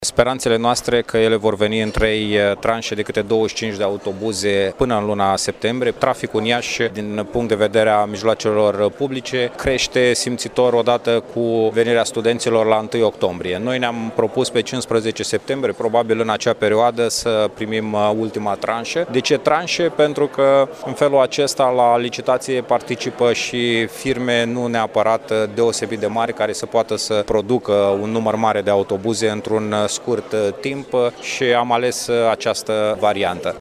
Viceprimarul Radu Botez a anunţat că acestea vor fi cumpărate în trei tranşe: